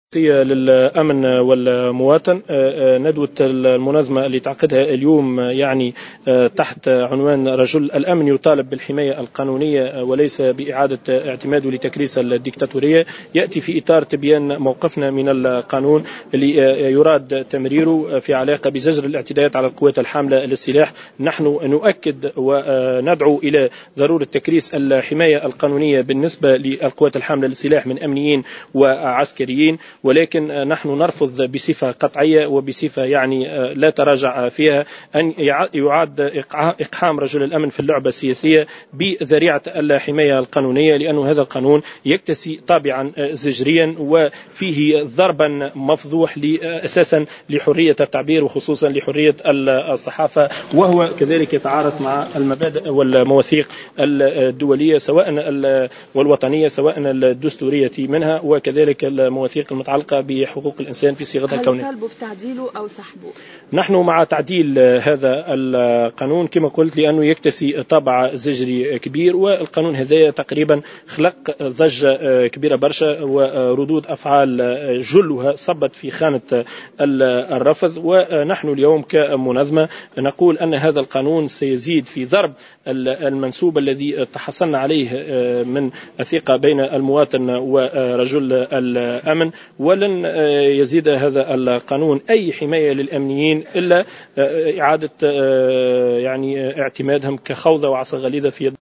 خلال ندوة صحفية